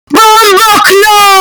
Loud Bomboclat Sound